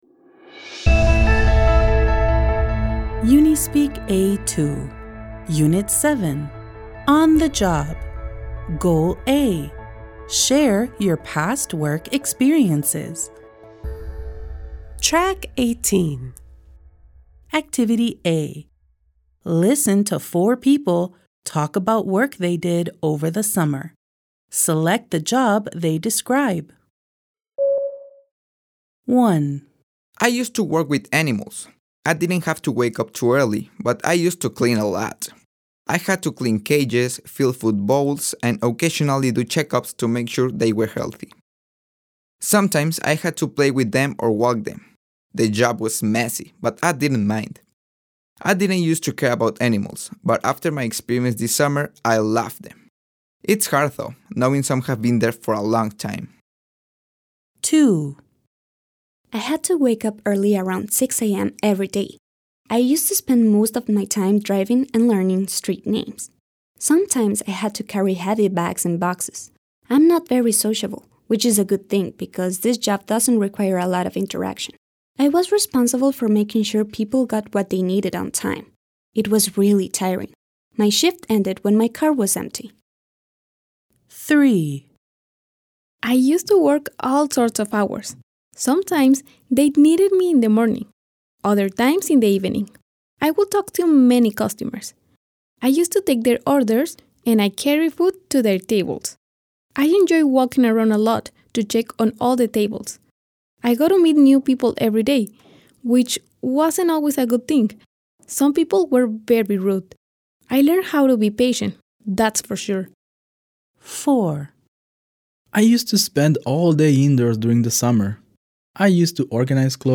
Goal A. Share Your Past Work Experiences. Activity A. Listen to four people talk about work they did over the summer.